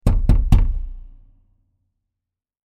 Triple Heavy Door Knock Sound Effect
Description: Triple heavy door knock sound effect. Hear a full-fist punch on the door with dramatic knocking. This powerful slam instantly creates tension and suspense.
Triple-heavy-door-knock-sound-effect.mp3